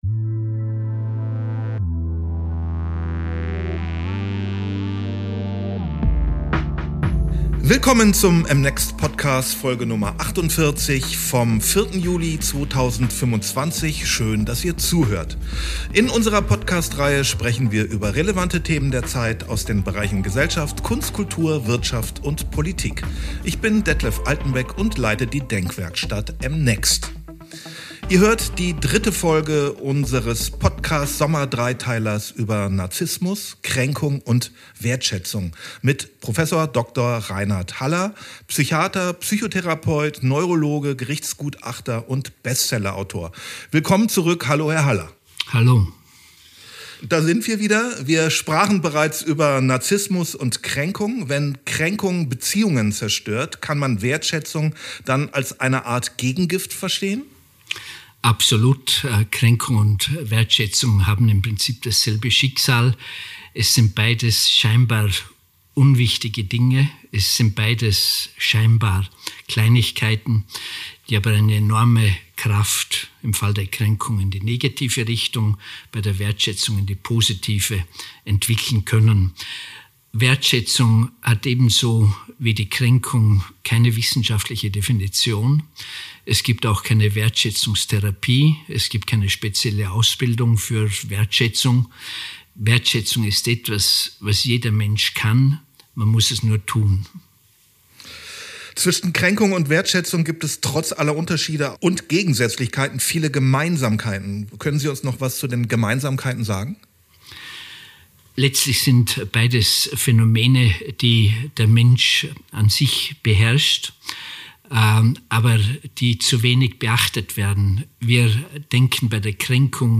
Wenn Kränkung Beziehungen zerstört, kann Wertschätzung wie ein Gegengift wirken, sagt Prof. Dr. Reinhard Haller in der dritten Folge unseres Sommergesprächs.